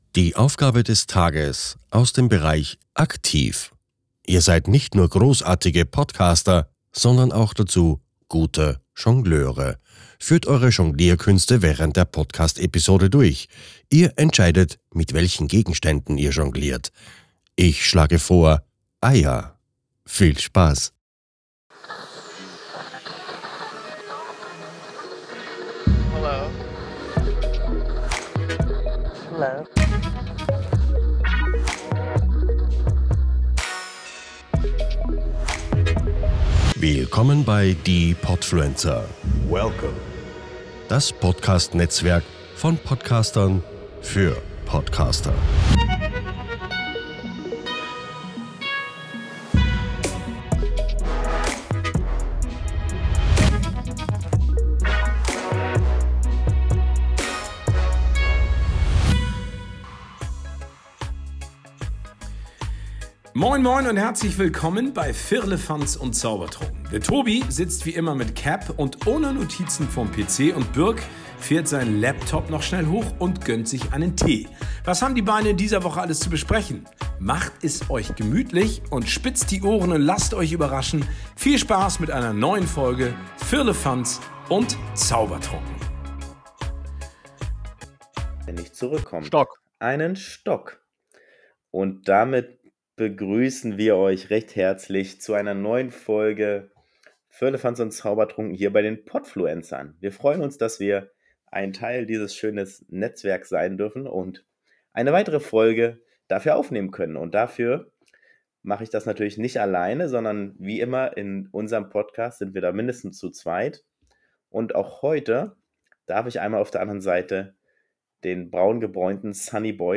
Mit viel TamTam und ordentlich Ärger mit dem Internet haben wir eine Folge für euch, die nicht anders realisierbar war. Mit der Aufgabe Jonglieren und Sportarten erklären, naja hört selbst.